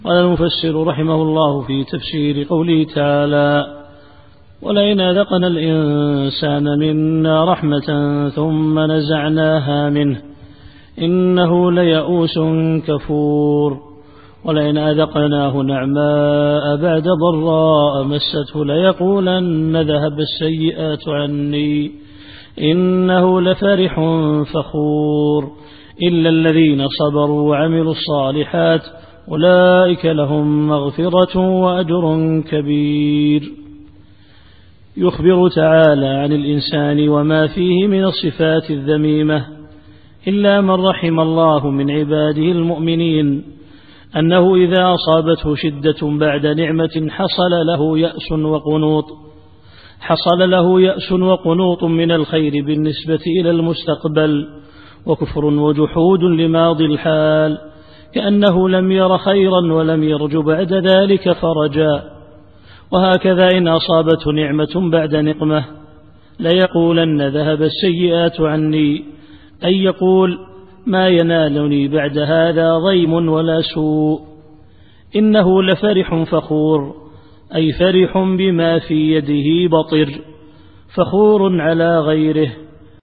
التفسير الصوتي [هود / 9]